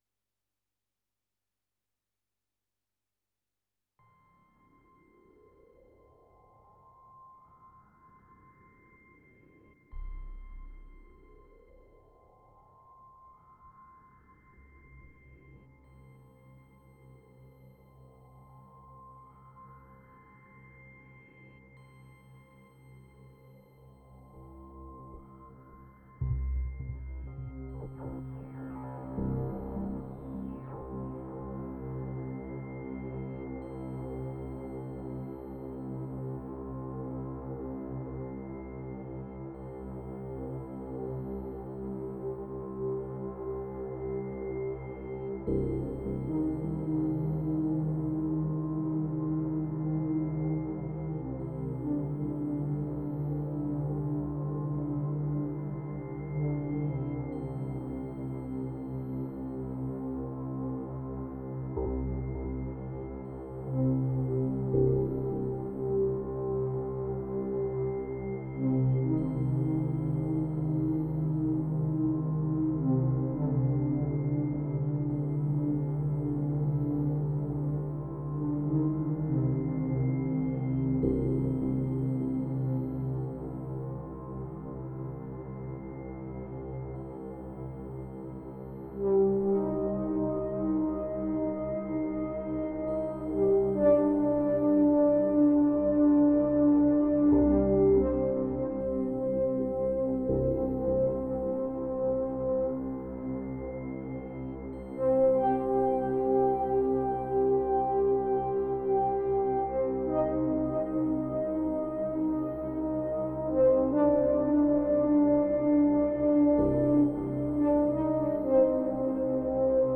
This is the earliest export I have in my files, it’s from 2019, think I got enamored by the little melody hook that was probably the first hook I had ever created in my life, just kept playing it live so it’s boringly repetitive.